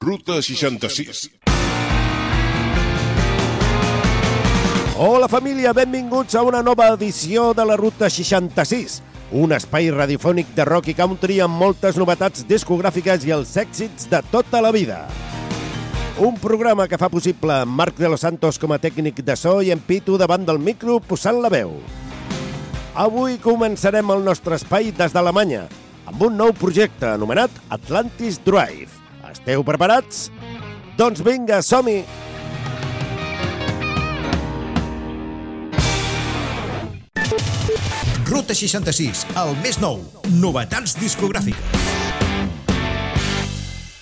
Indicatiu del programa, presentació, equip i indicatiu